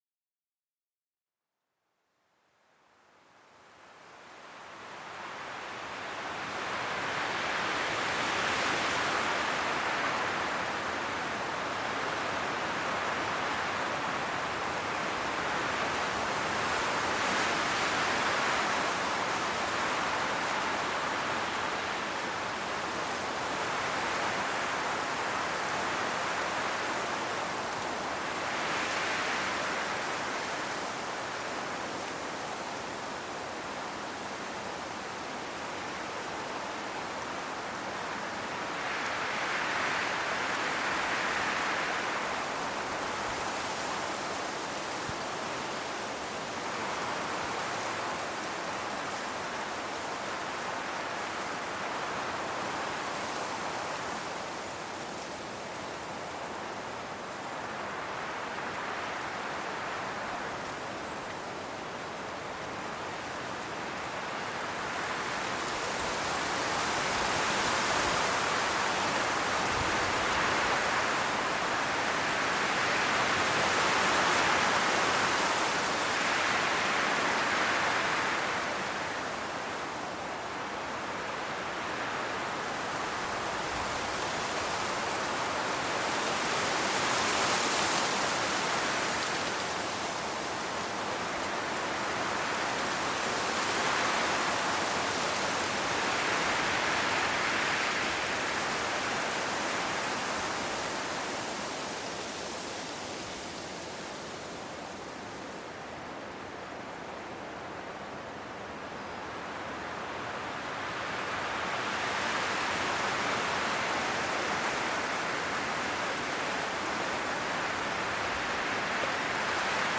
RUHE INS OHR: Sommerbrise-Geheimnis mit warmem Wind-Flüstern